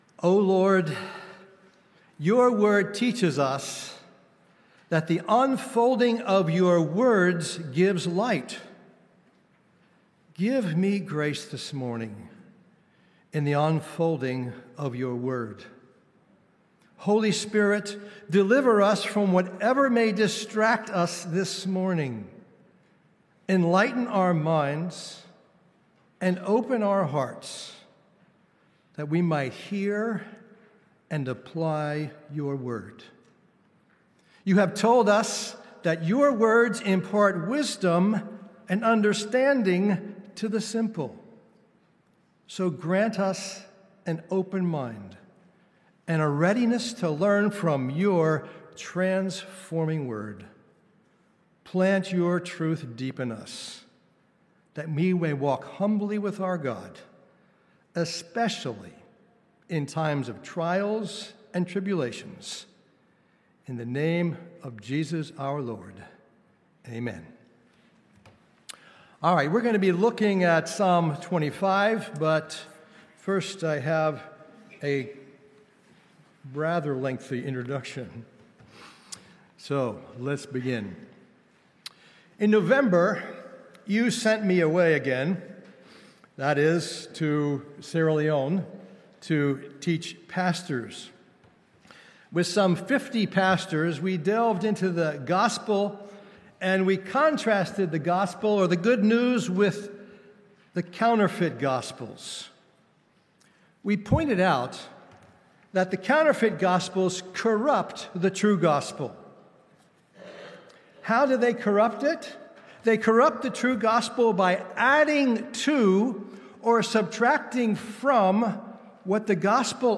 Sermons on Psalm 25 — Audio Sermons — Brick Lane Community Church